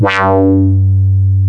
WAH 1.wav